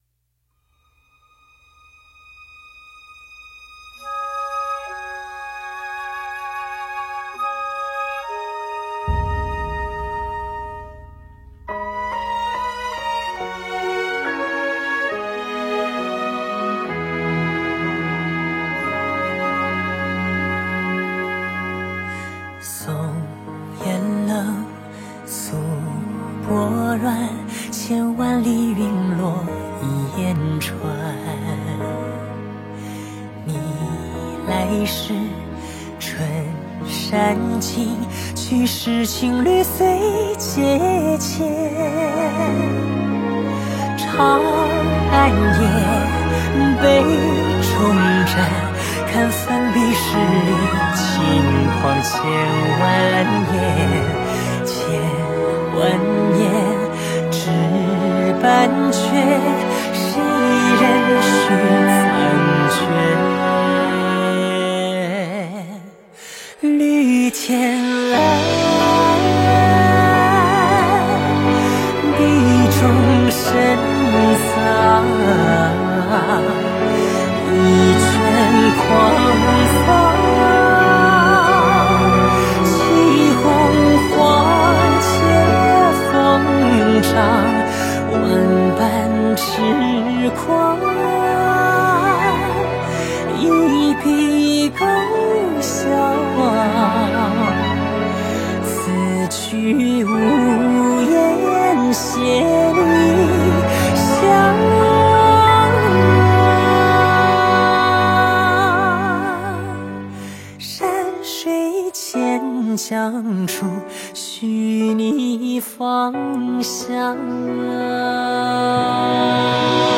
佛音 诵经 佛教音乐 返回列表 上一篇： 智慧流星咒 下一篇： 蝶 相关文章 云水禅心 云水禅心--风潮唱片...